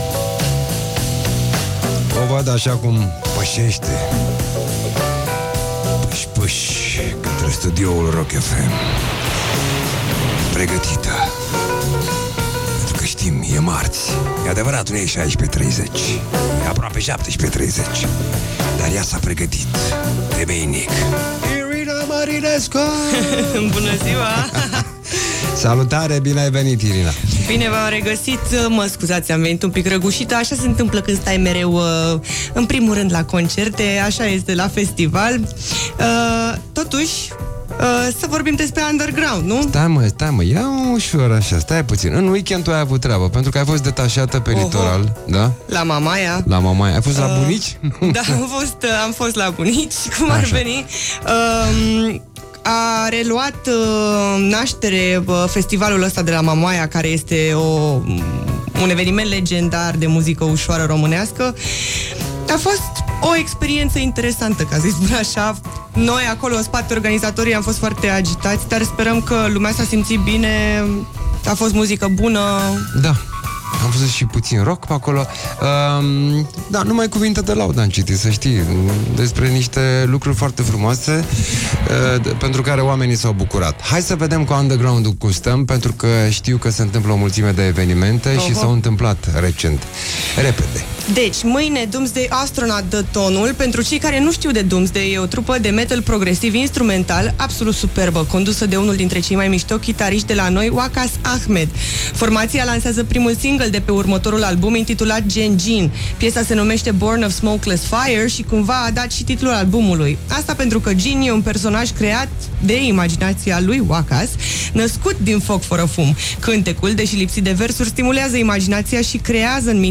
Așa cum, probabil, v-am obișnuit, am vorbit și azi răgușită la radio.